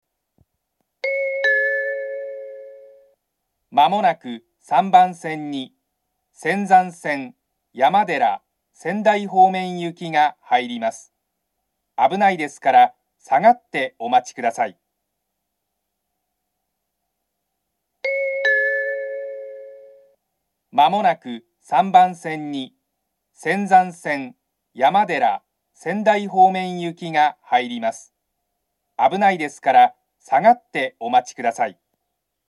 ３番線上り接近放送